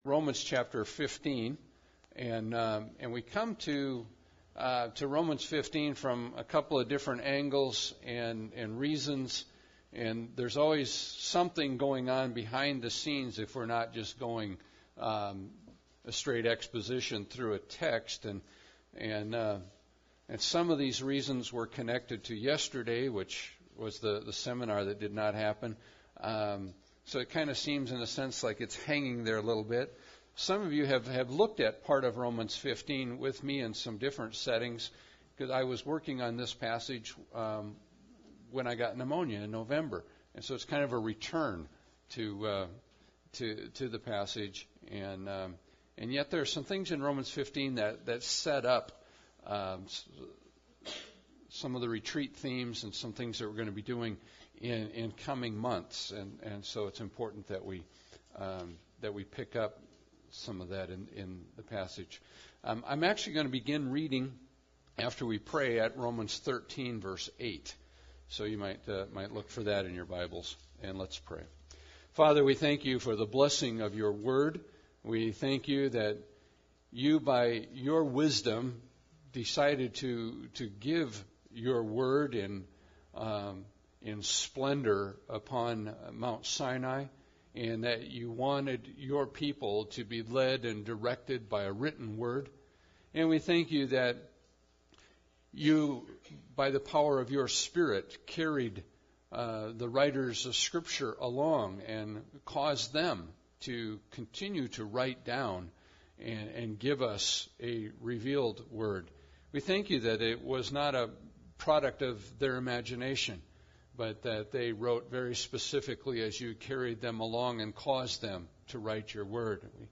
Passage: Romans 15:1-13 Service Type: Sunday Service